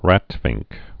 (rătfĭngk)